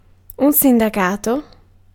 Ääntäminen
Synonyymit faith belief hope confidence expectation confide rely on have confidence in have faith have faith in entrustment Ääntäminen : IPA : /tɹʌst/ US : IPA : [tɹʌst] Lyhenteet ja supistumat tr.